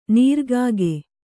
♪ nīrg`ge